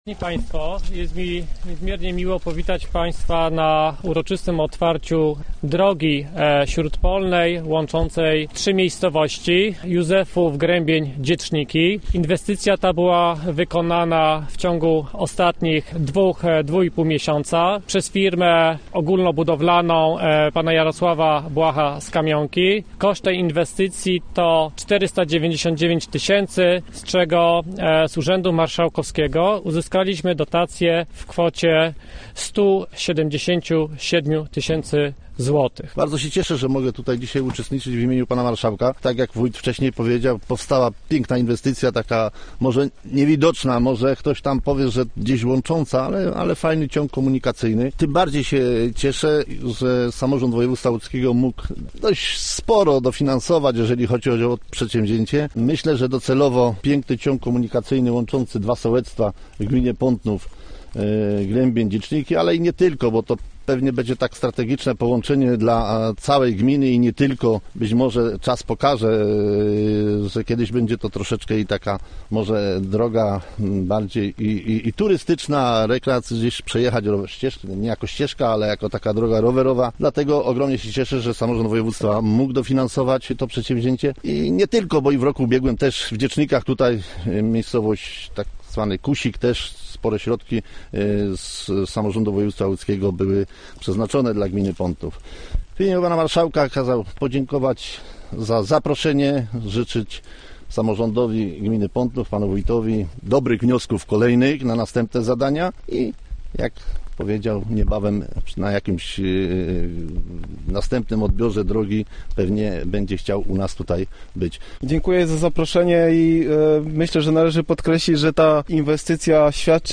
W piątek oficjalnie przecięto wstęgę przy inwestycji drogowej w gminie Pątnów.